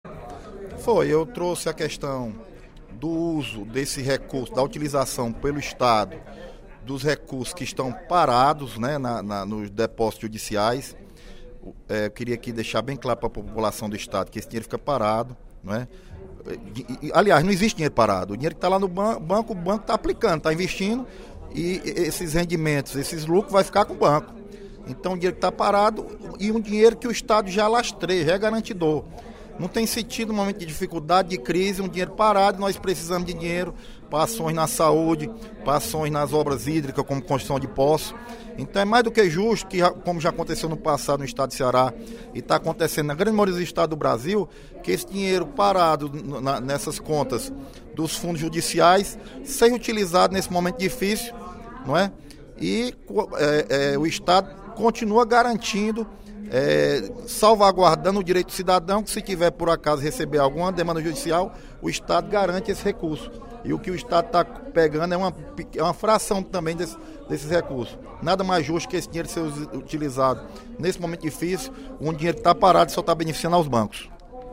O deputado Leonardo Pinheiro (PSD) defendeu, em pronunciamento no primeiro expediente da sessão plenária da Assembleia Legislativa desta quinta-feira (29/10), a aprovação da mensagem do Governo do Estado que pede autorização para a utilização dos recursos do fundo dos depósitos em juízo.